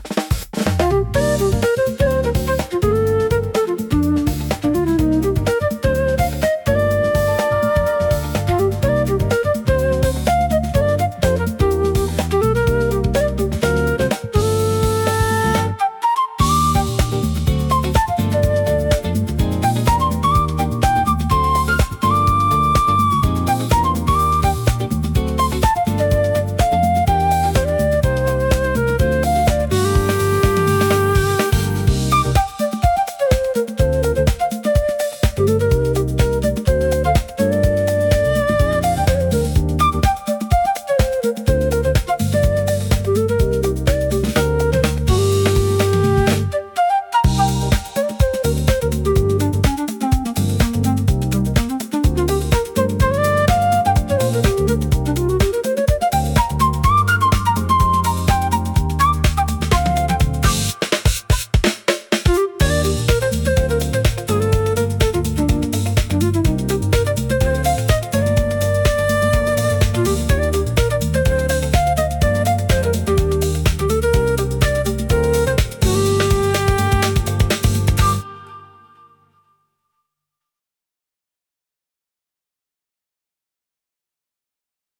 インストゥルメンタル
イメージ：インスト,軽やか,おしゃれ